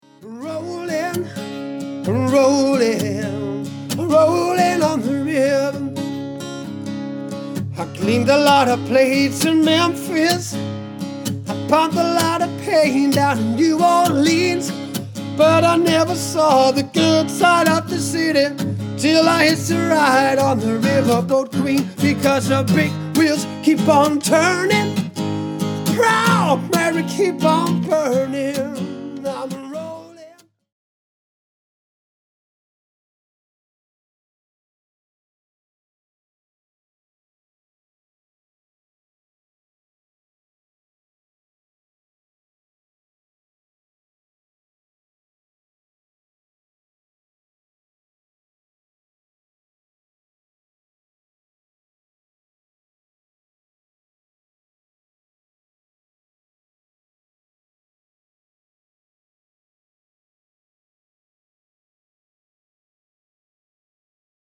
Jeg spiller guitar og synger, og det er det bedste jeg ved.